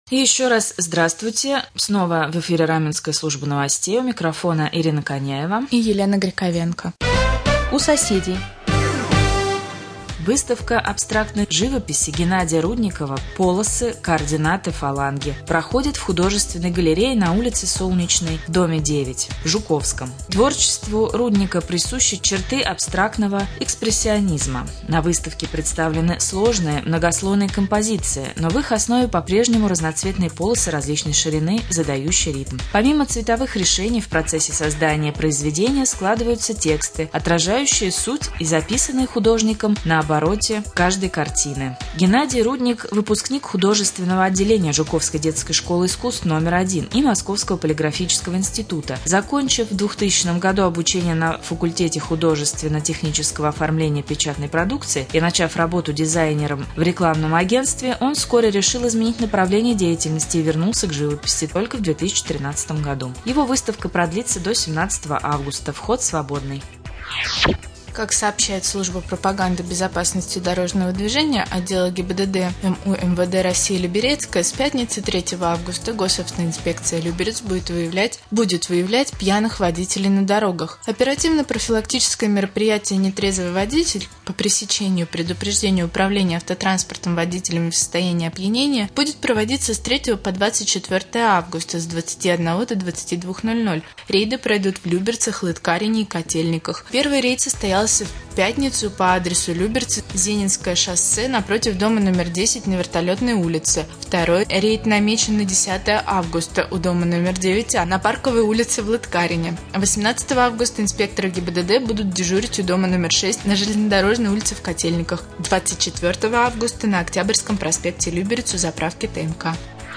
Новостной блок